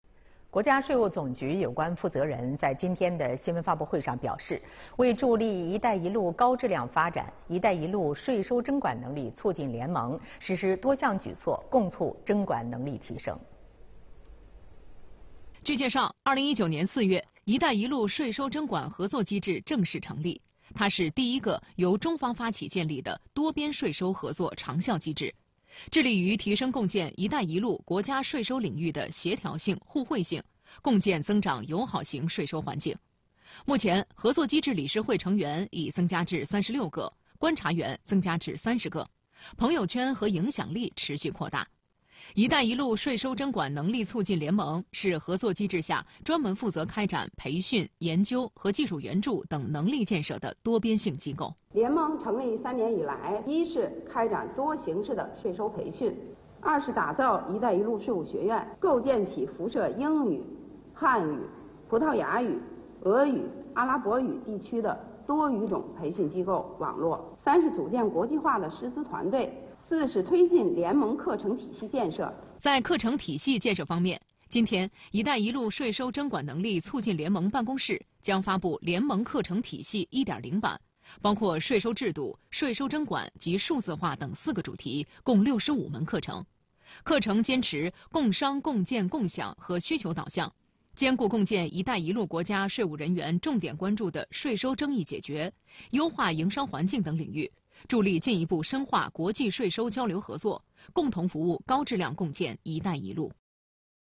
国家税务总局有关负责人在2月21日的新闻发布会上表示，为助力“一带一路”高质量发展，“一带一路”税收征管能力促进联盟实施多项举措，共促征管能力提升。